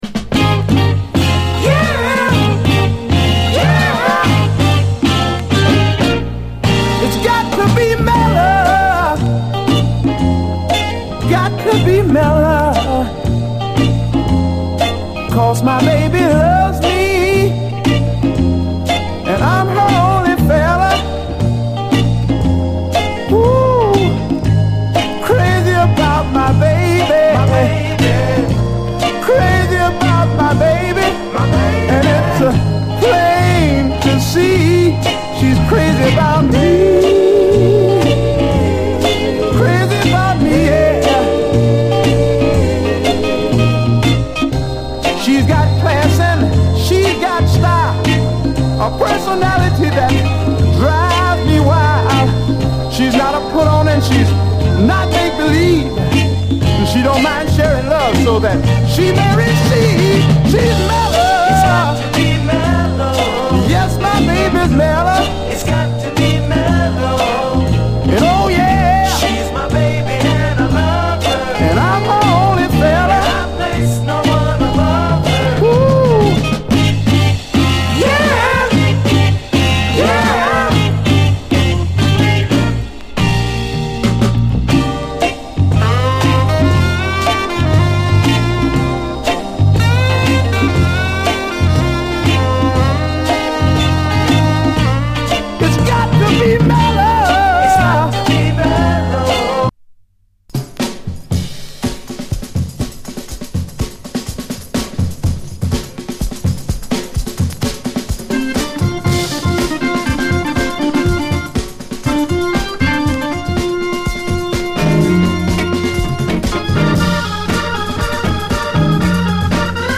60's SOUL, SOUL
ドラム・ブレイク入りオルガン・ファンク
黄金のミディアム・スウィート・ソウル！